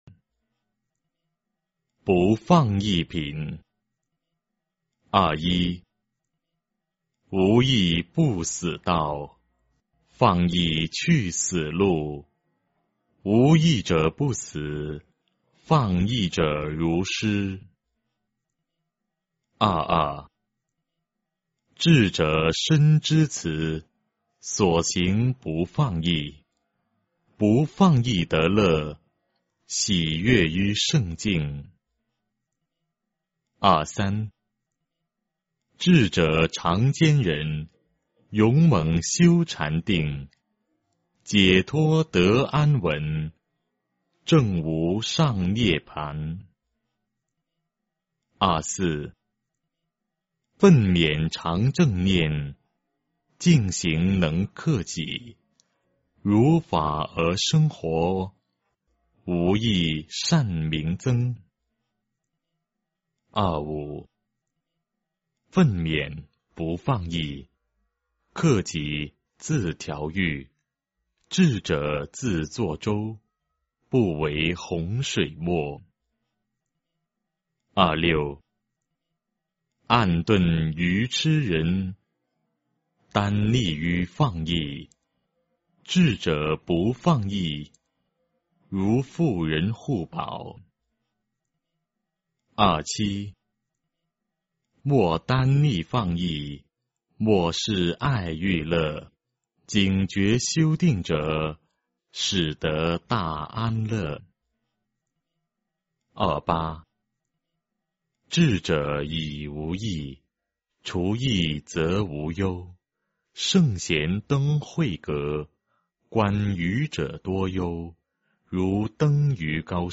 法句经-不放逸品 - 诵经 - 云佛论坛